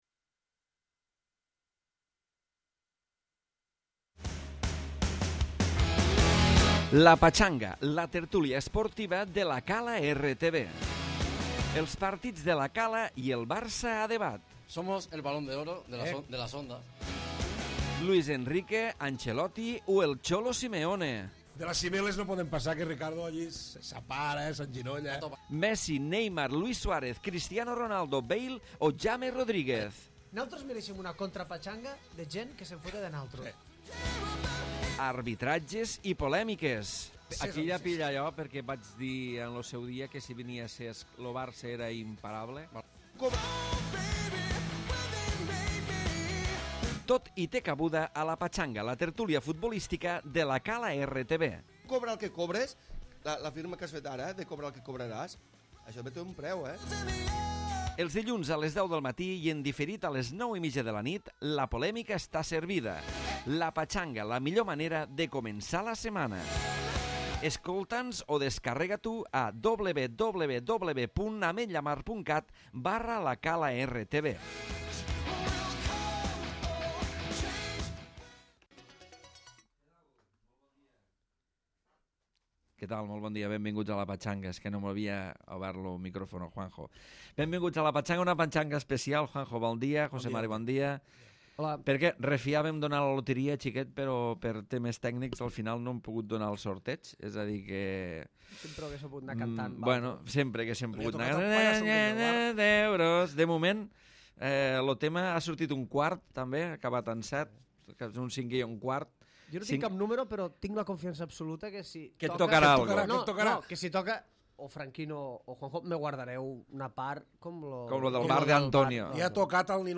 Tertúlia d'actualitat futbolistica amb la polèmica a flor de pell.